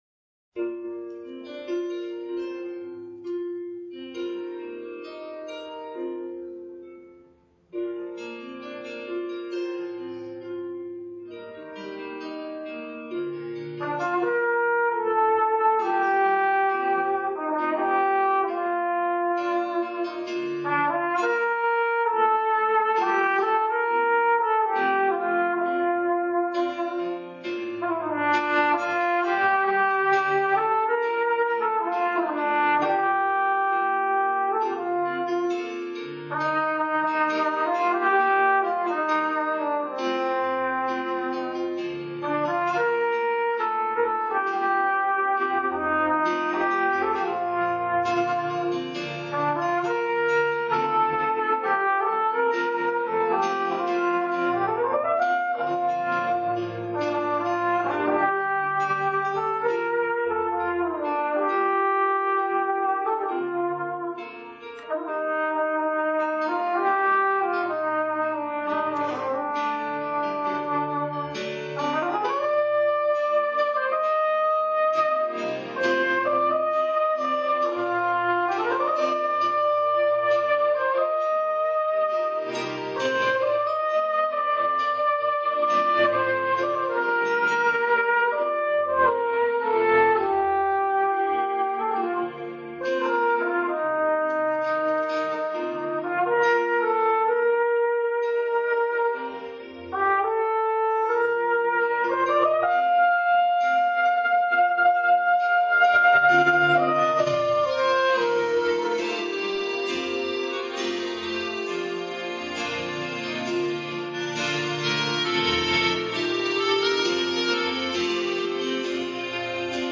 Sunday Evening Music - January 15, 2012
Trumpet Solo